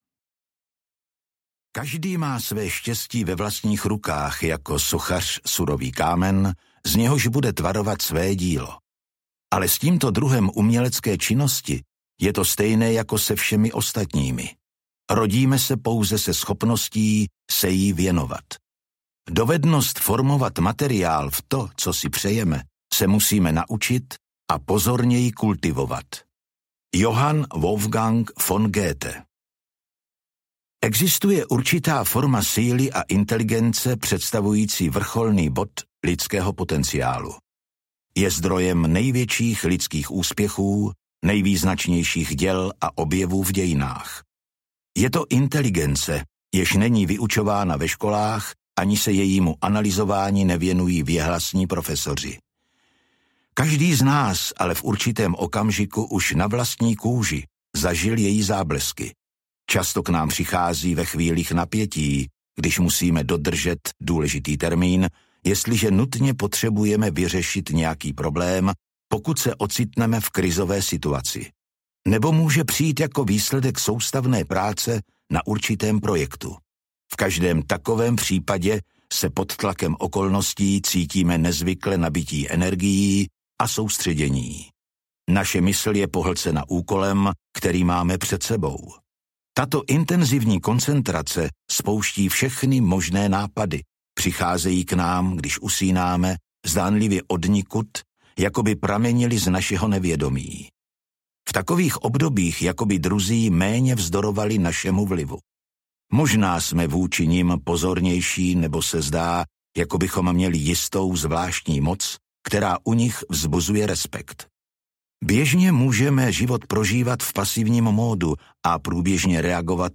Mistrovství audiokniha
Ukázka z knihy